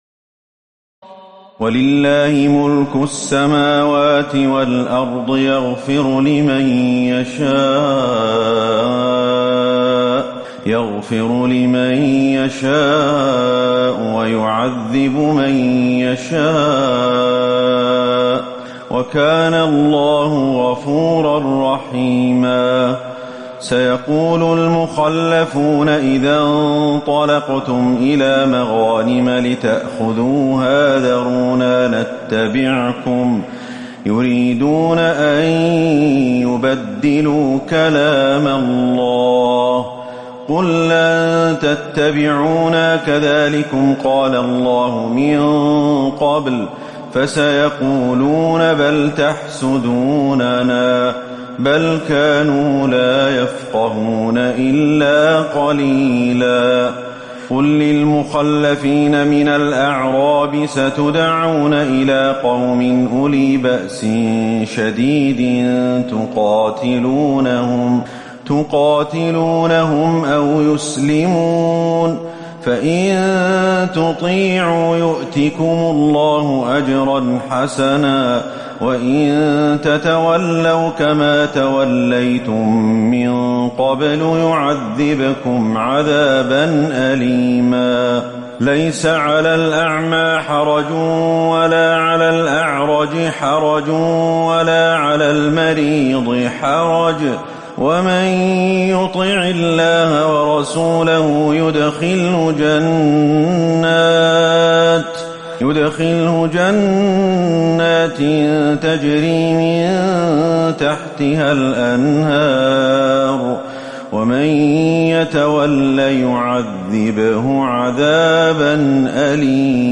تراويح ليلة 25 رمضان 1438هـ من سور الفتح (14-29) الحجرات و ق و الذاريات (1-23) Taraweeh 25 st night Ramadan 1438H from Surah Al-Fath and Al-Hujuraat and Qaaf and Adh-Dhaariyat > تراويح الحرم النبوي عام 1438 🕌 > التراويح - تلاوات الحرمين